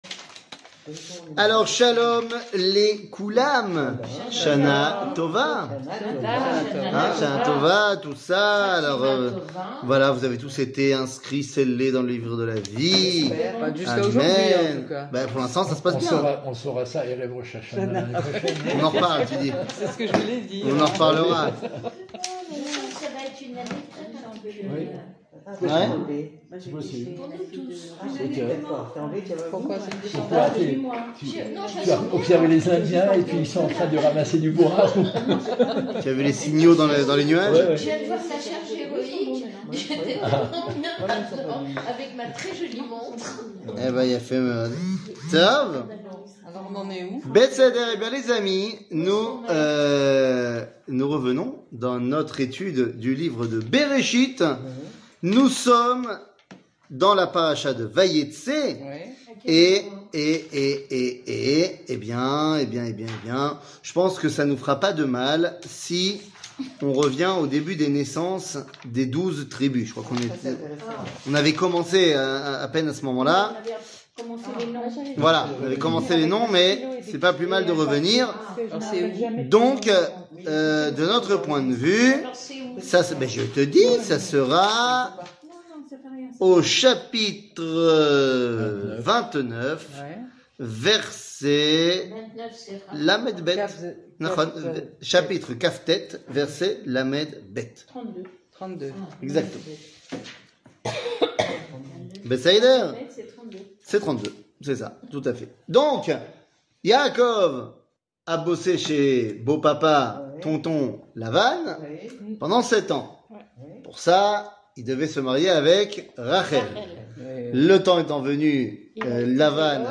קטגוריה Livre de berechit 00:57:35 Livre de berechit שיעור מ 19 אוקטובר 2022 57MIN הורדה בקובץ אודיו MP3